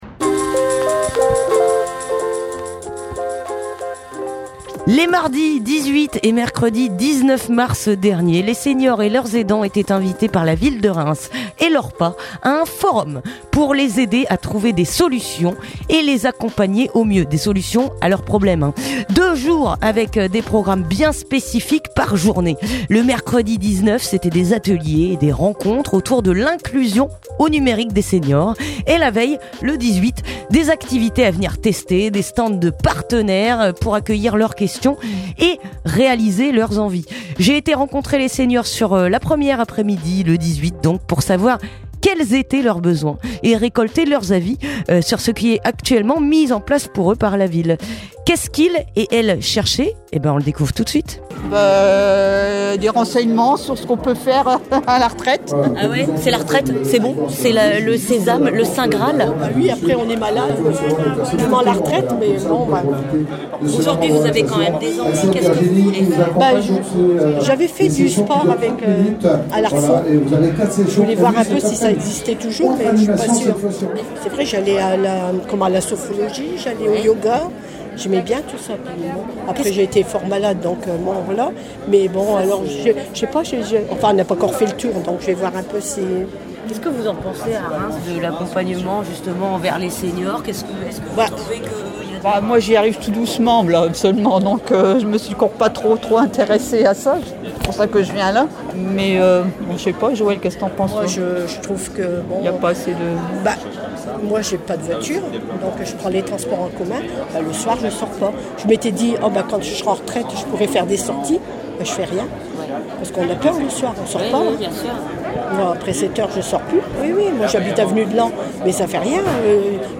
Reportage au stade Auguste Delaune